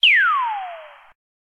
06_Laser.ogg